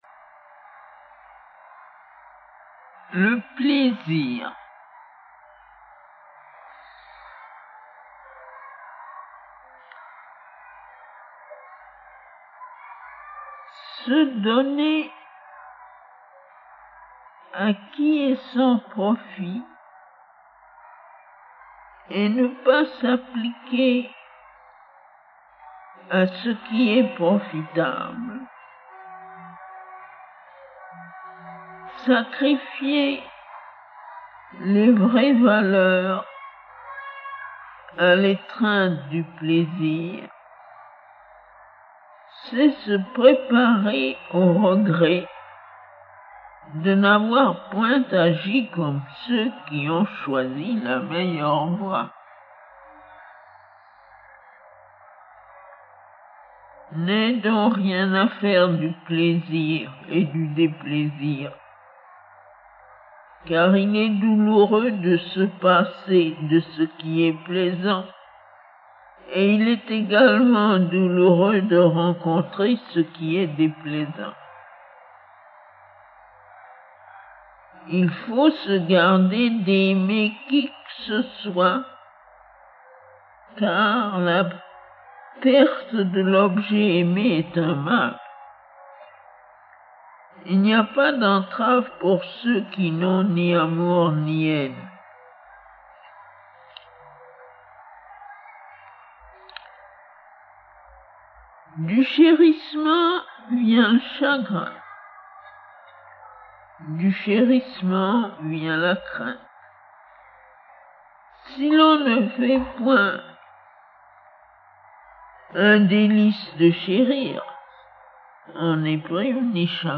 Mère. Commentaires sur le Dhammapada (1957-58): Tape recordings